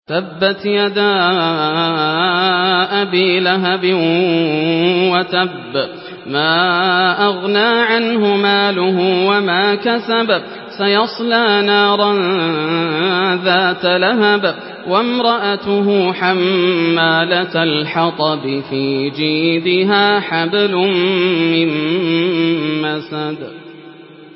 Sourate Al-Masad MP3 à la voix de Yasser Al Dosari par la narration Hafs
Une récitation touchante et belle des versets coraniques par la narration Hafs An Asim.
Murattal Hafs An Asim